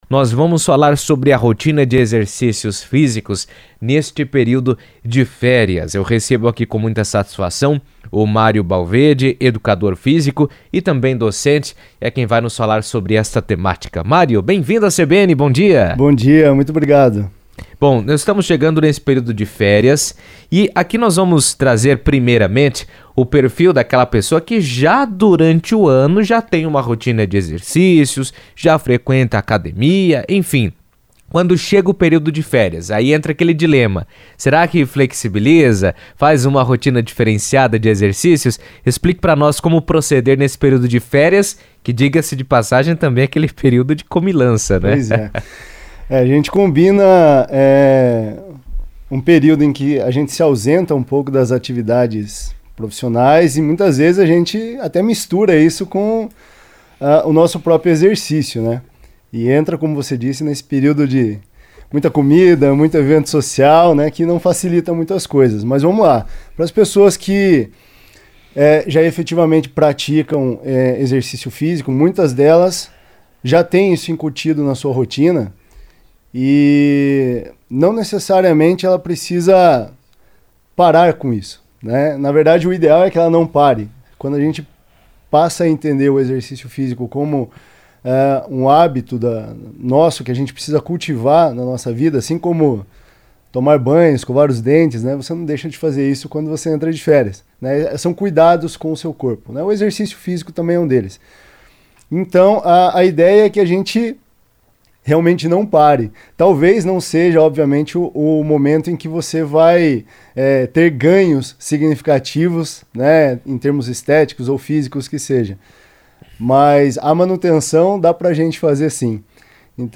educador físico e docente, falou sobre o tema em entrevista à CBN